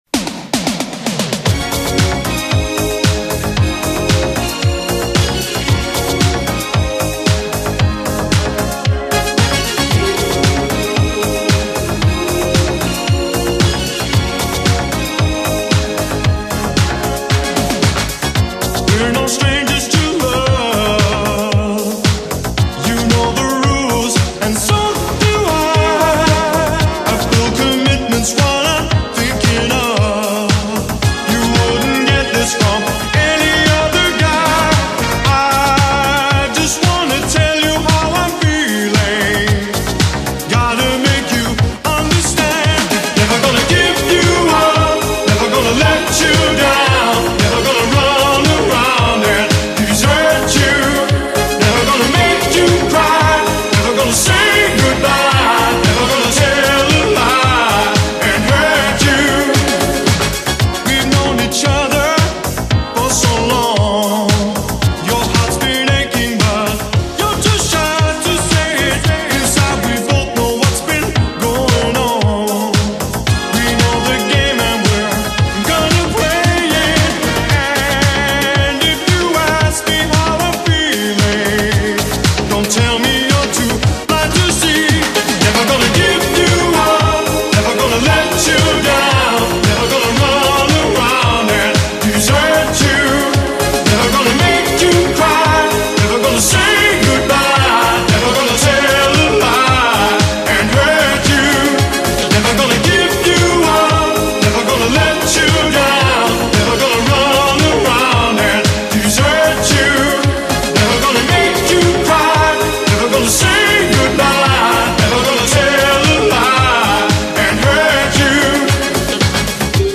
BPM113
Audio QualityCut From Video